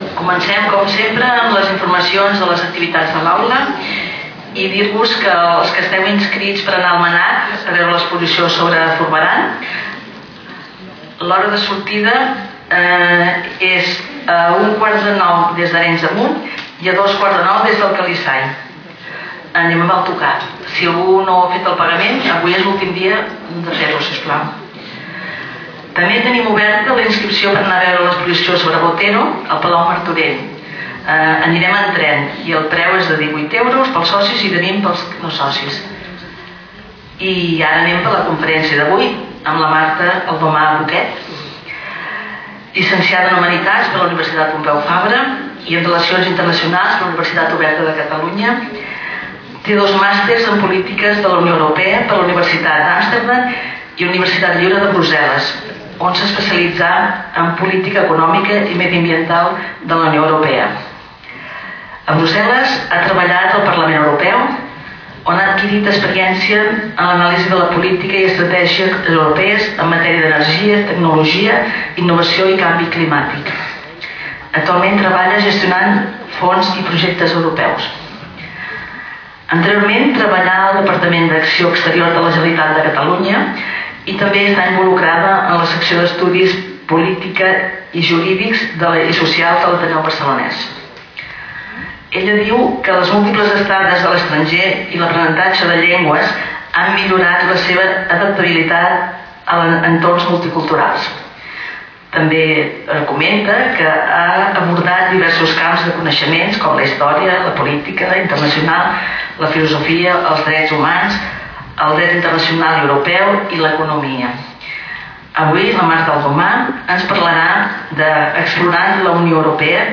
Lloc: Sala d'actes del Col.legi La Presentació
Categoria: Conferències